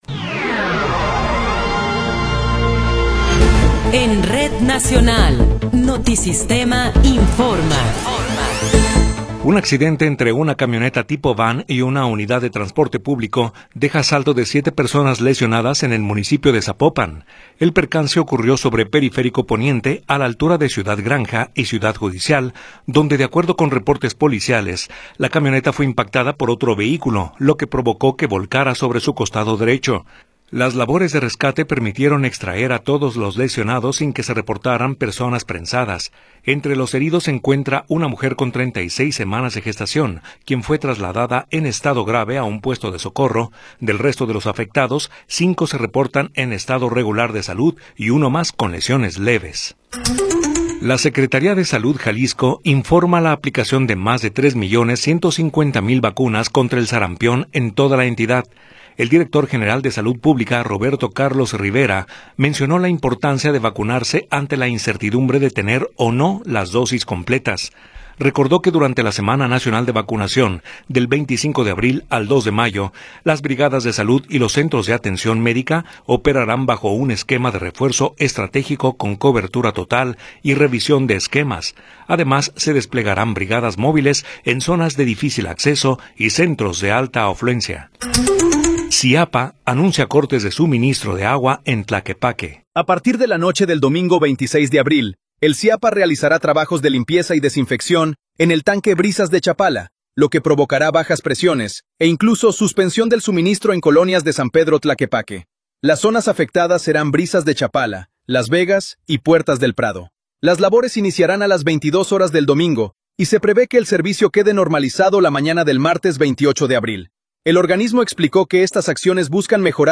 Noticiero 18 hrs. – 24 de Abril de 2026
Resumen informativo Notisistema, la mejor y más completa información cada hora en la hora.